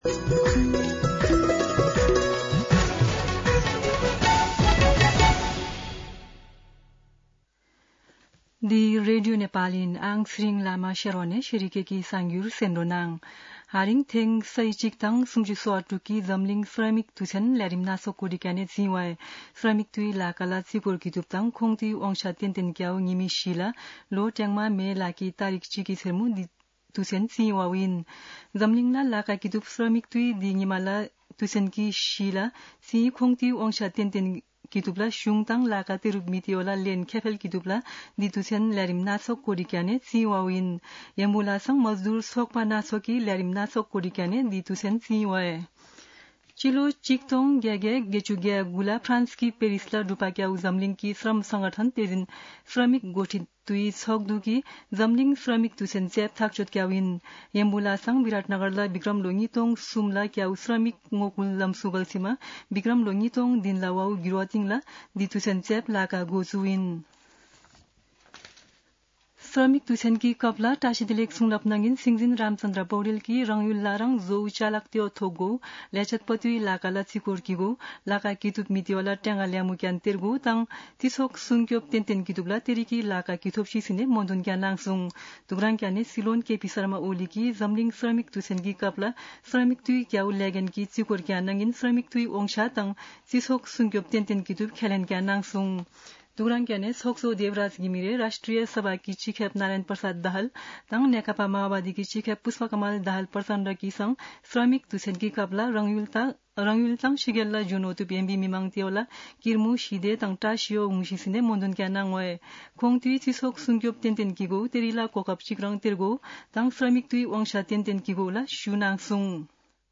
शेर्पा भाषाको समाचार : १८ वैशाख , २०८२
sherpa-News-01-18.mp3